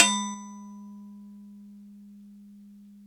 bell ding percussion spackle-knife sound effect free sound royalty free Sound Effects